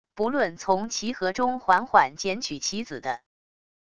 不论从棋盒中缓缓捡取棋子的wav音频